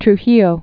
(tr-hēō, -yō)